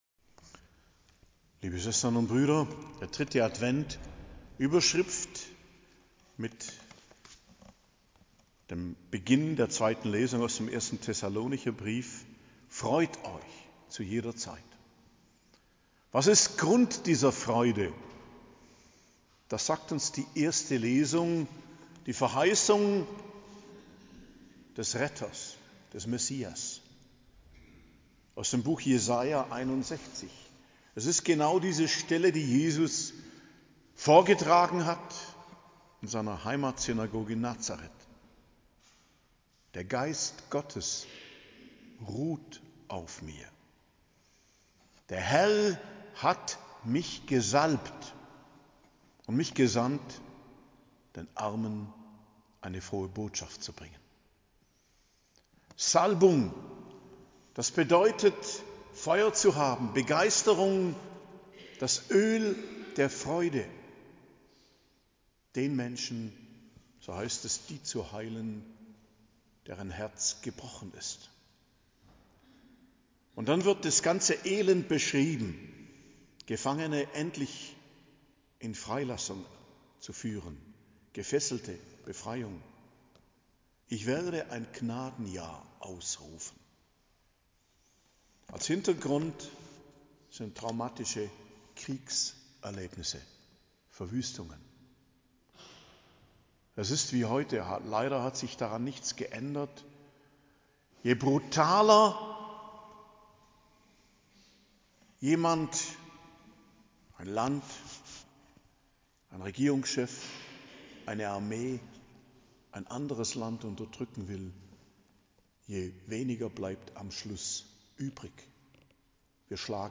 Predigt zum 3. Adventssonntag, 17.12.2023